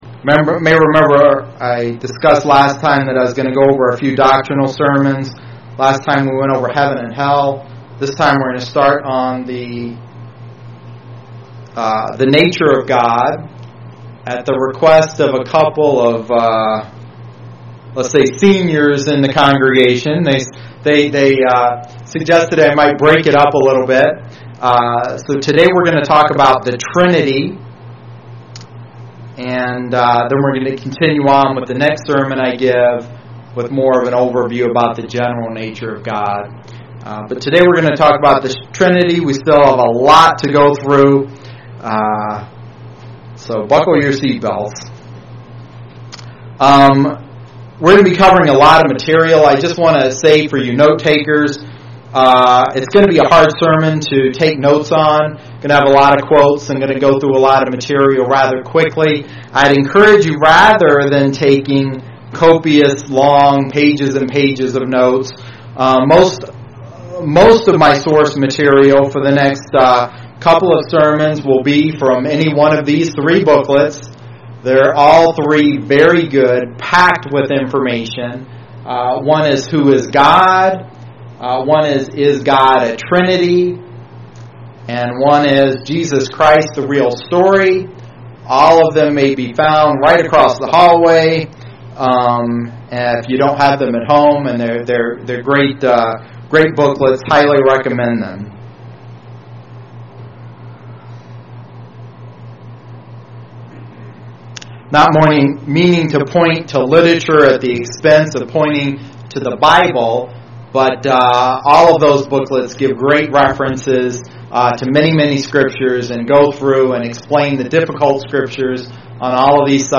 Given in Lansing, MI
UCG Sermon